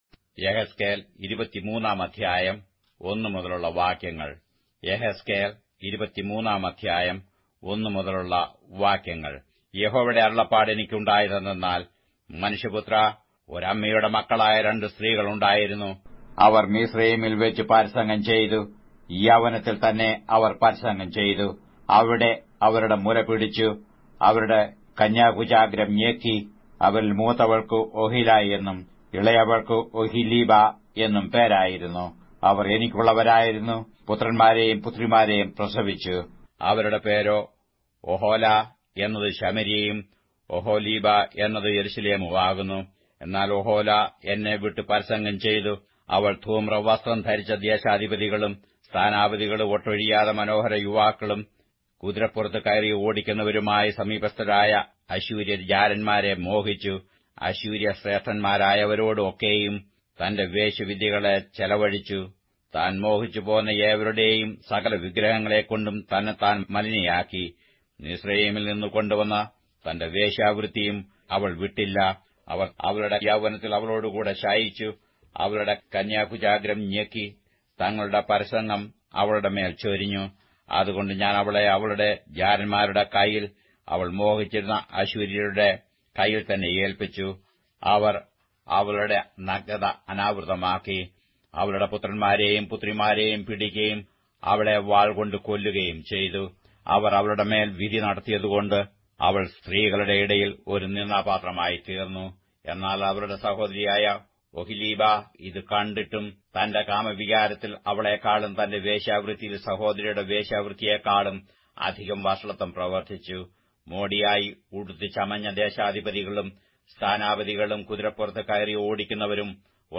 Malayalam Audio Bible - Ezekiel 37 in Litv bible version